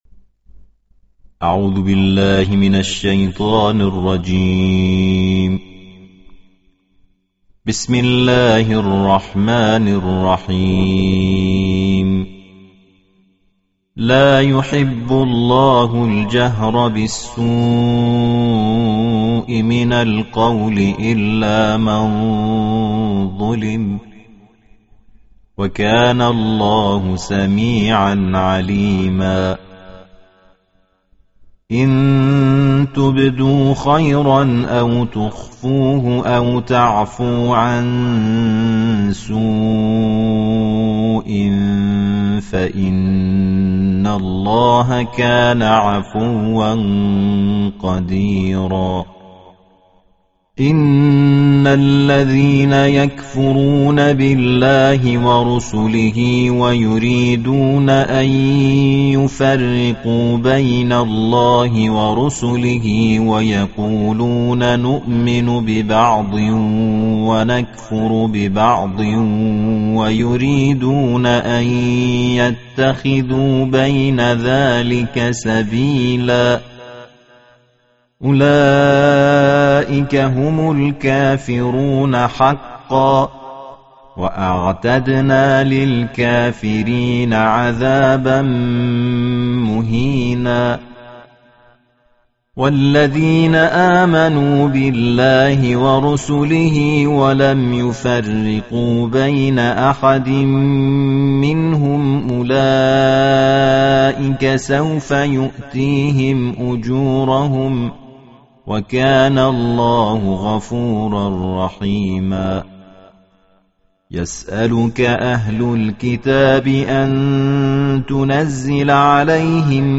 Bacaan Tartil Juzuk Keenam Al-Quran